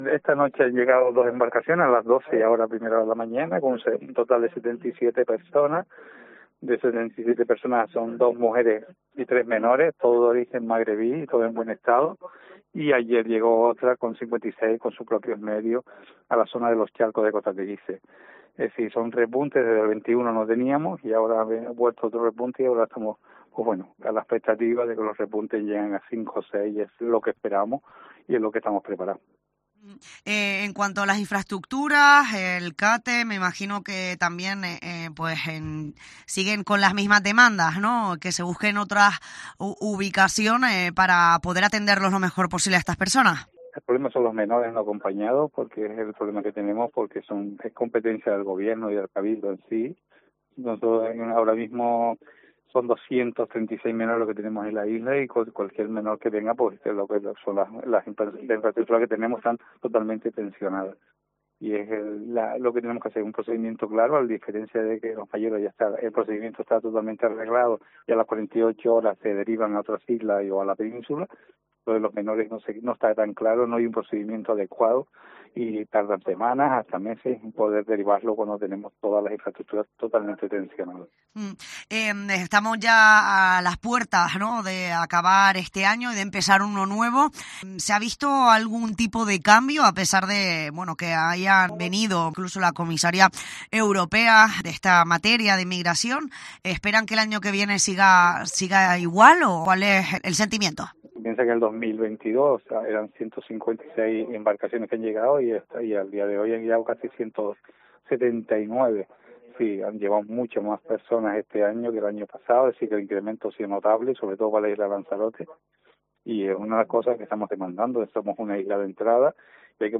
Según apunta en nuestros micrófonos, el mayor problema que tiene ahora mismo en la isla es la atención a los menores migrantes no acompañados, porque todos los recursos e infraestructuras derivados para ellos están tensionadas.